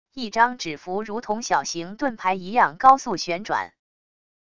一张纸符如同小型盾牌一样高速旋转wav音频